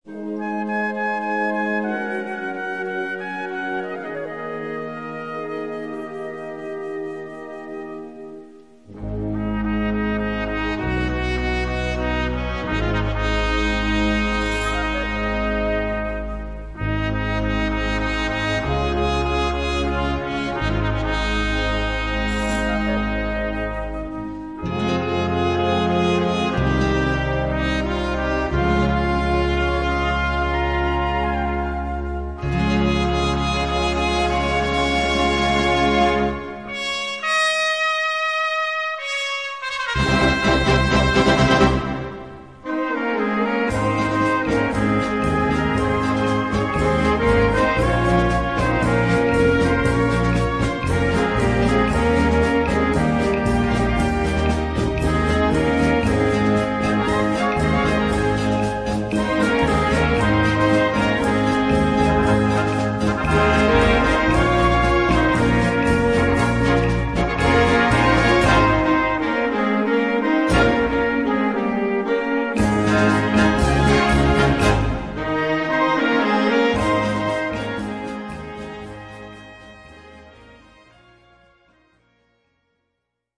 Gattung: Rumba
Besetzung: Blasorchester